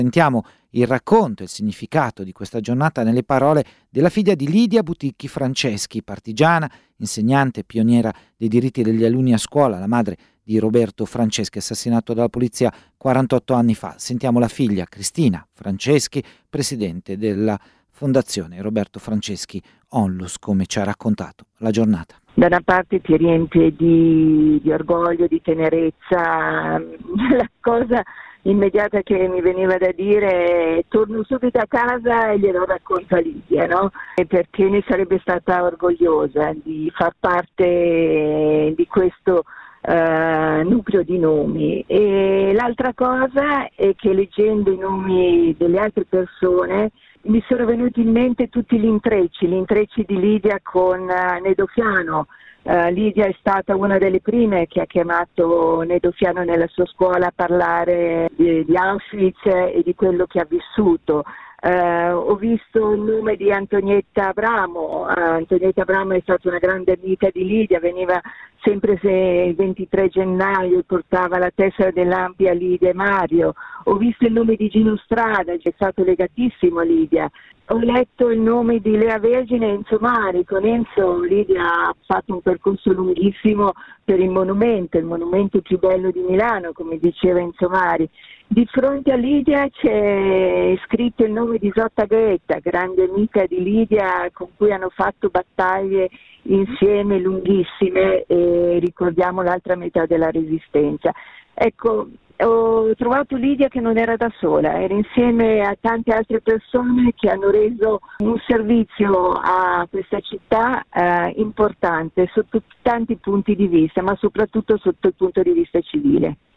a Metroregione su Radio Popolare - edizione delle 19:48 del 2 novembre 2021